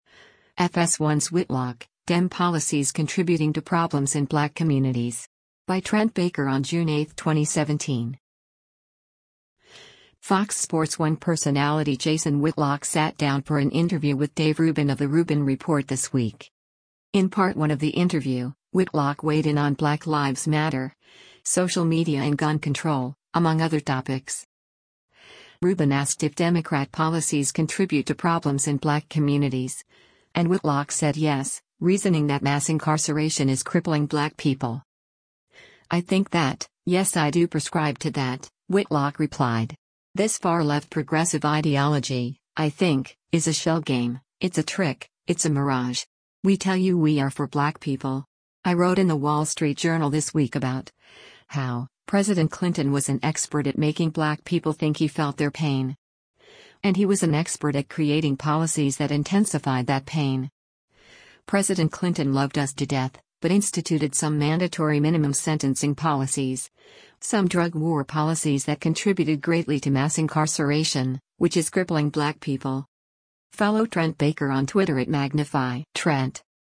Fox Sports 1 personality Jason Whitlock sat down for an interview with Dave Rubin of “The Rubin Report” this week.